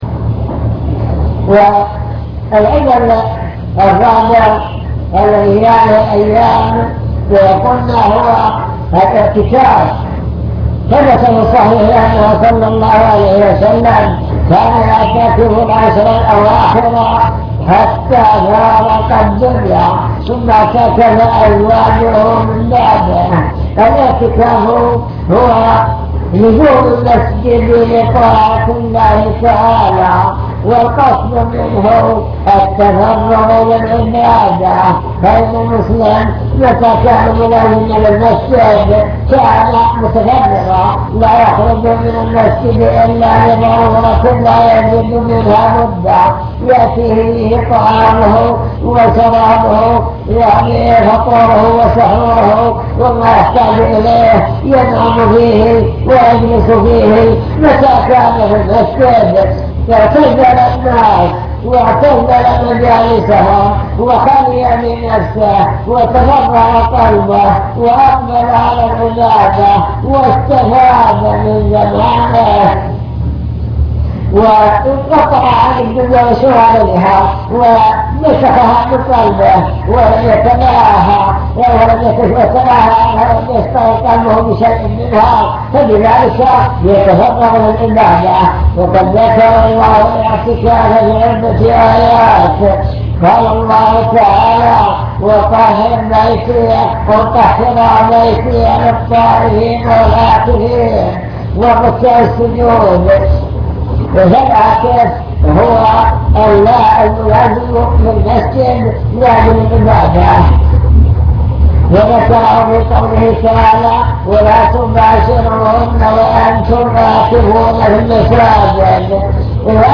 المكتبة الصوتية  تسجيلات - محاضرات ودروس  مجموعة محاضرات ودروس عن رمضان العشر الأواخر من رمضان